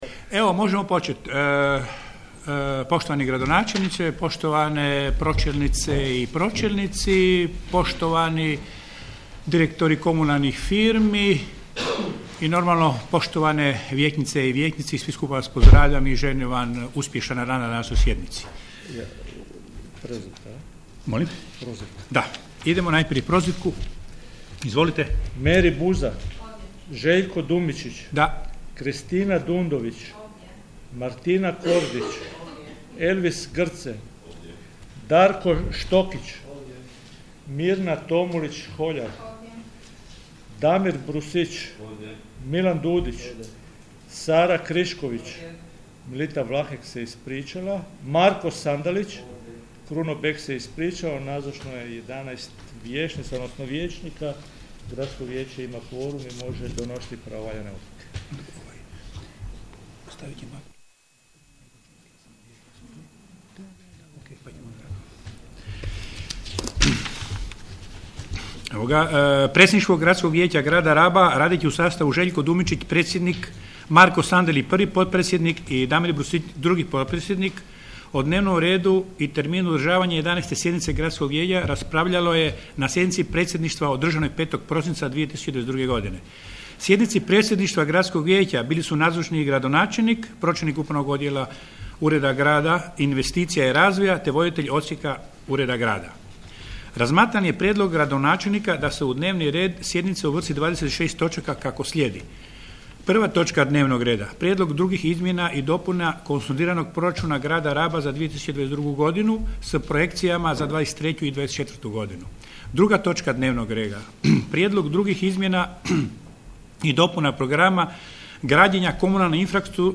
11. sjednica Gradskog vijeća - prvi dio